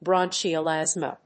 Bronchial+Asthma.mp3